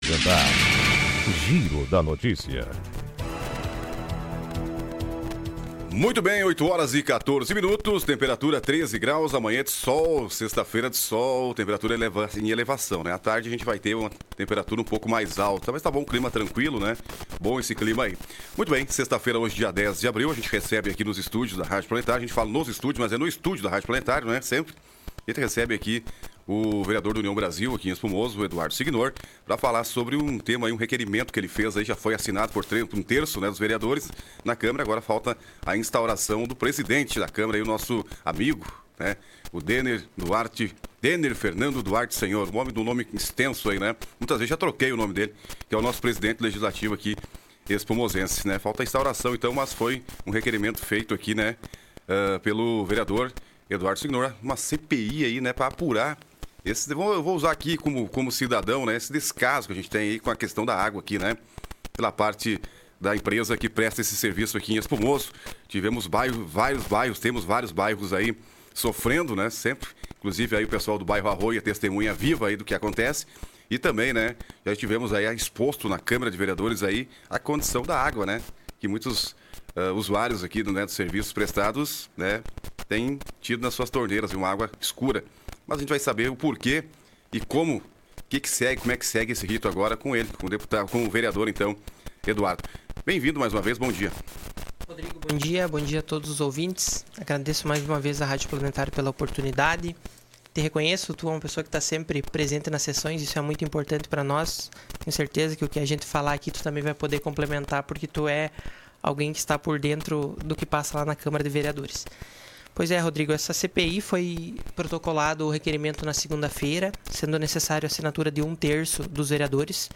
Na manhã desta sexta-feira (10), o vereador Eduardo Signor (União Brasil) participou de entrevista na Rádio Planetário, onde falou sobre o requerimento protocolado na Câmara de Vereadores que solicita a abertura de uma Comissão Parlamentar de Inquérito (CPI) para investigar os recorrentes problemas no abastecimento de água no município de Espumoso.